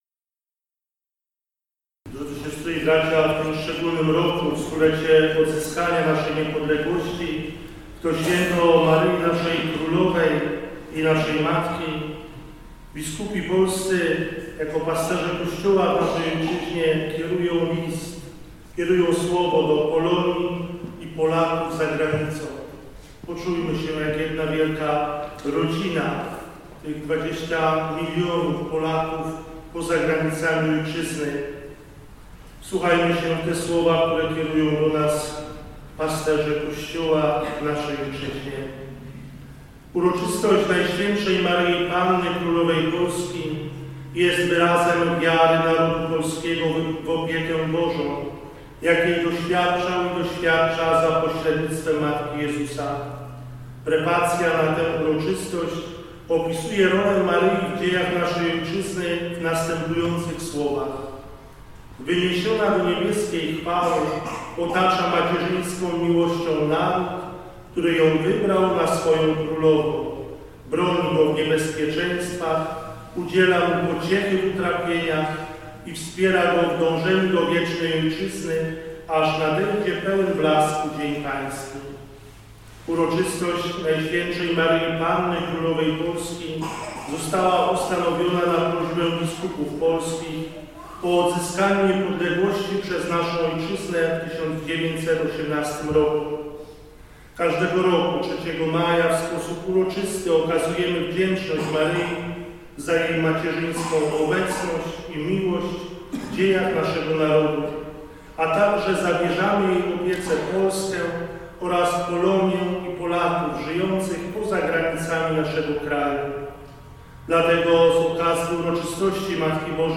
Istotnym elementem Mszy �w. - zamiast homilii - by� List Biskup�w Polskich do polonii i Polak�w za granic�